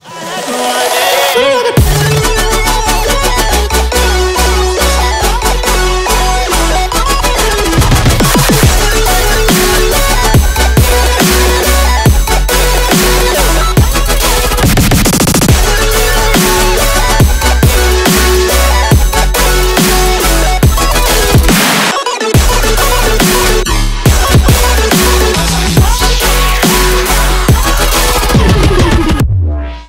Электроника
громкие